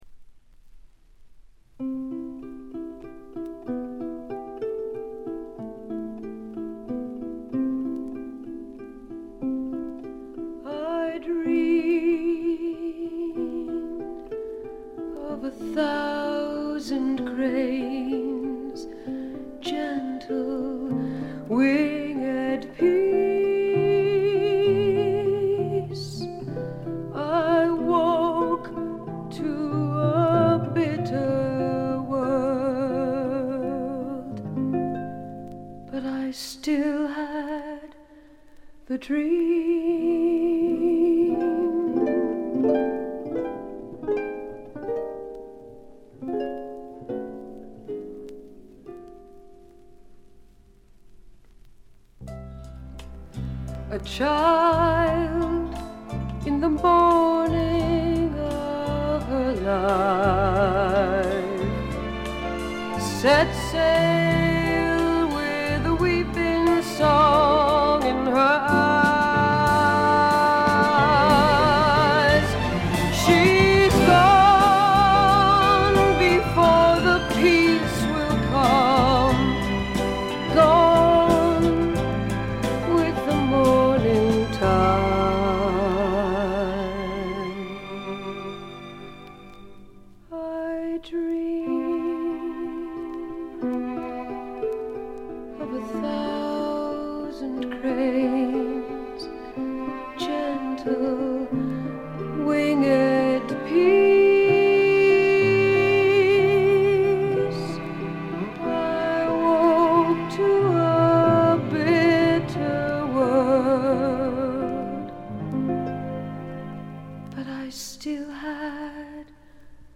部分試聴ですが軽微なバックグラウンドノイズ、チリプチ程度。
フェミニスト系の女性シンガソングライター
試聴曲は現品からの取り込み音源です。